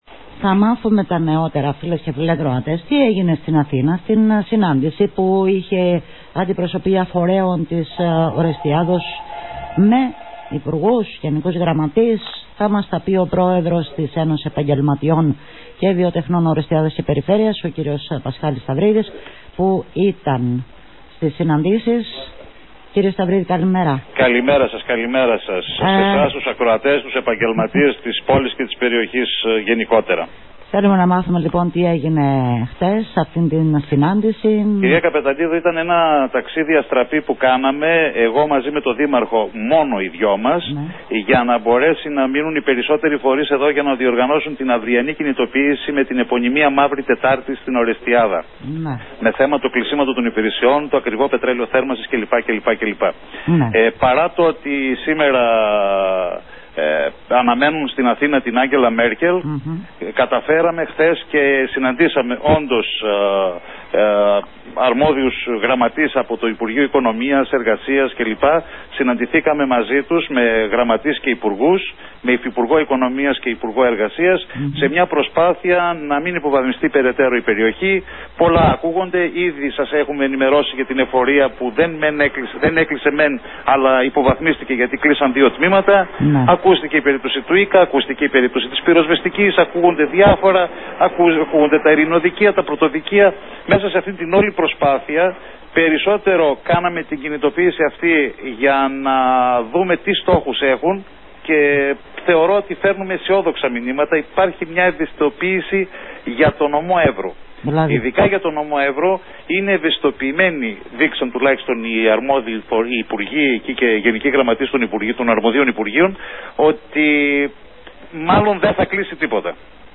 Στις συναντήσεις που είχε χτες η αντιπροσωπεία φορέων της πόλης με επικεφαλής τον Δήμαρχο στην Αθήνα αναφέρθηκε στην εκπομπή “Ματιές στην Ενημέρωση”